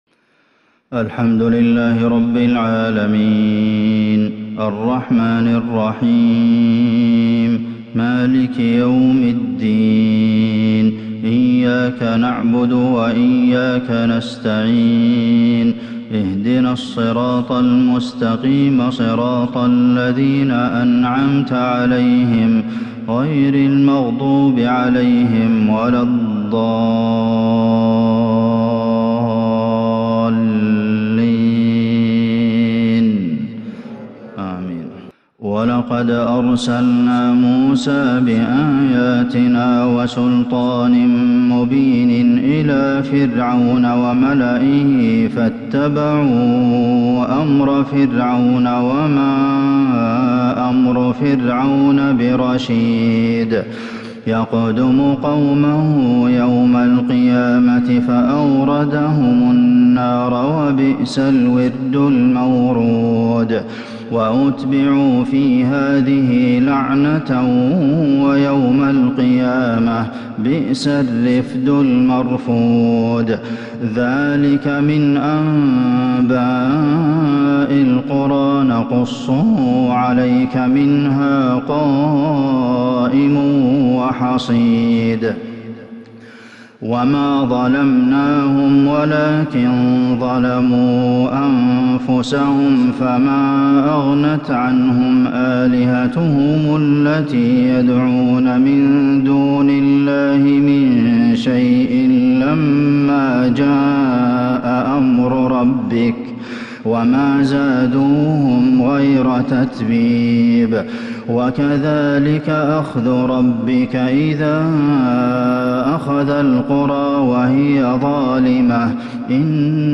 فجر الخميس 6-7-1442هـ من سورة هود | Fajr prayer from Surah Hud 18/2/2021 > 1442 🕌 > الفروض - تلاوات الحرمين